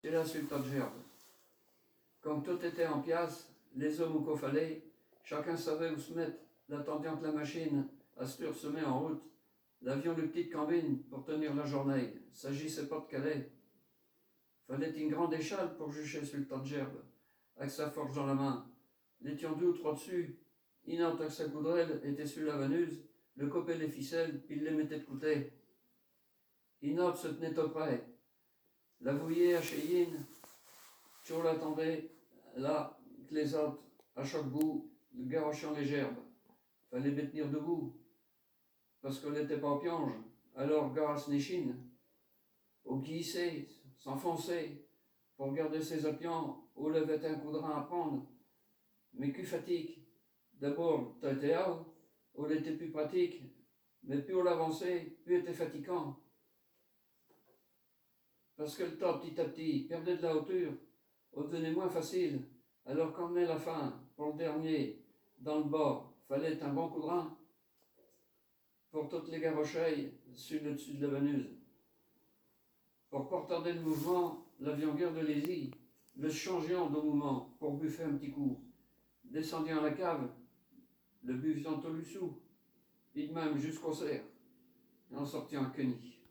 Genre poésie
Poésies en patois